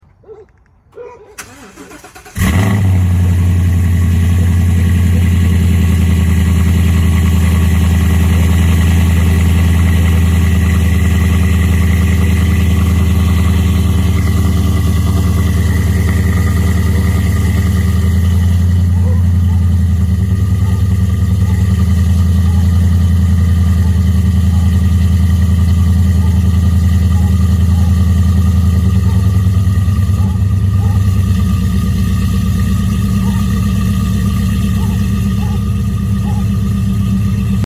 7.3 Gas ProCharged F 250 Super sound effects free download
7.3 Gas ProCharged F-250 Super Duty Cold Start.